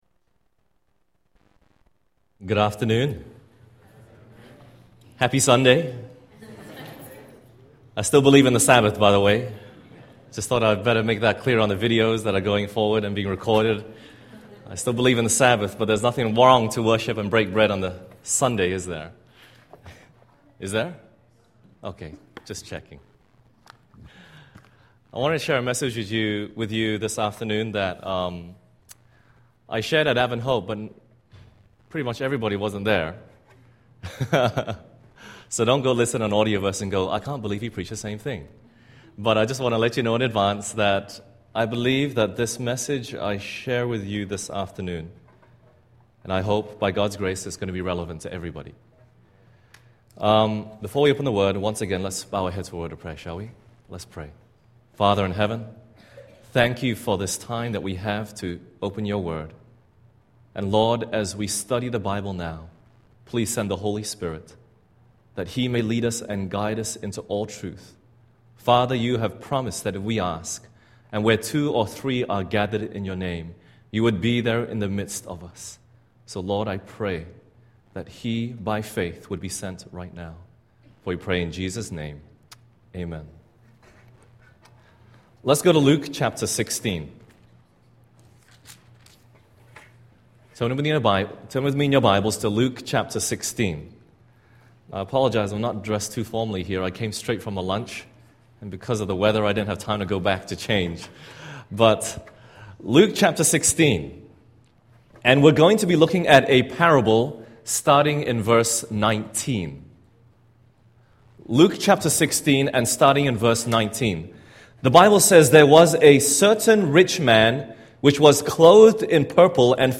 Seventh-day Adventist Church in Bloomington, CA
Special Presentatoin
Audio Sermons